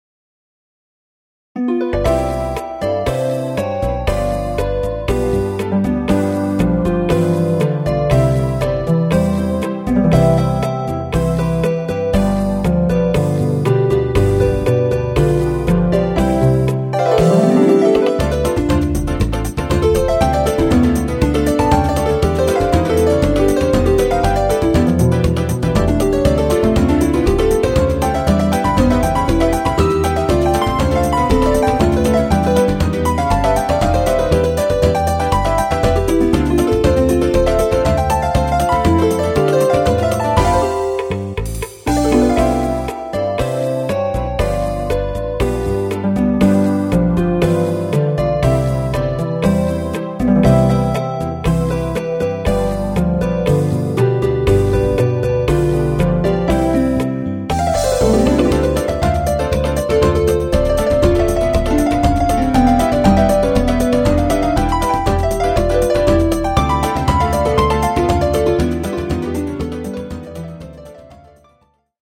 この方はどこまでもラテンなノリなんですね。